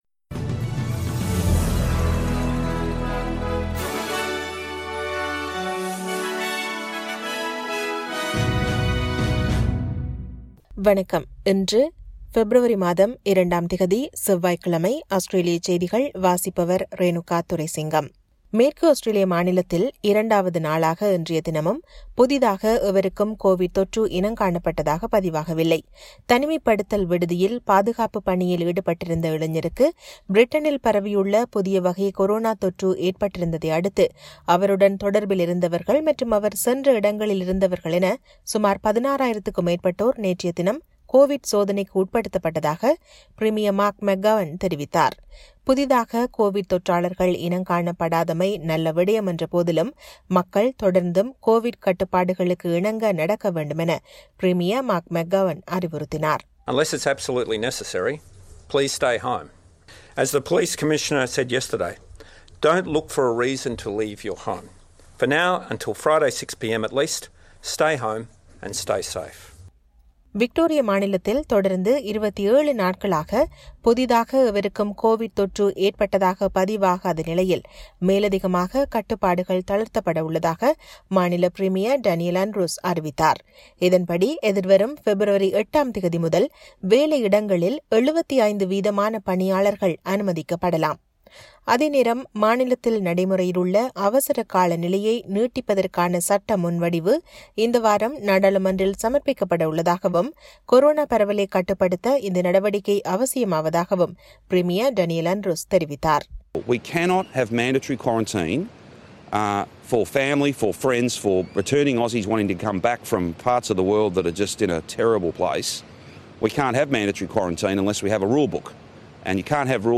Australian news bulletin for Tuesday (02 Feb 2021).